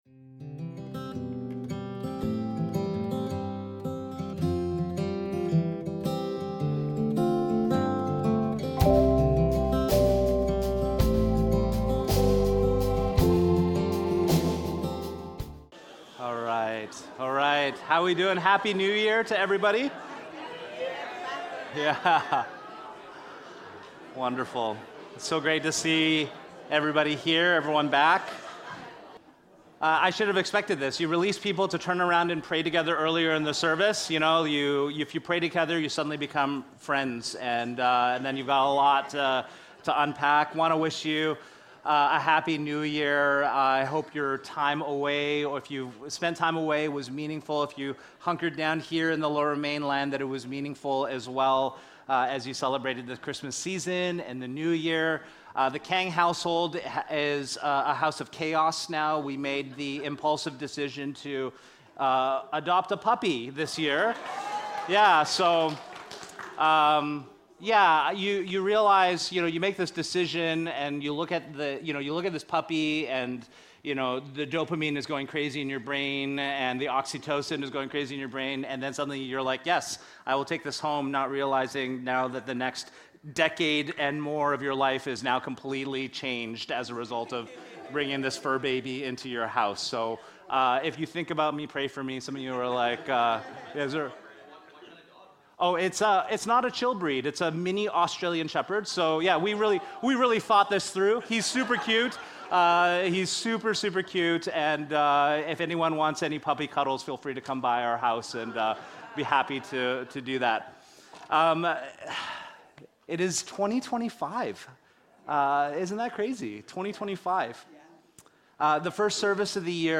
This Sunday was a special prayer and worship Sunday.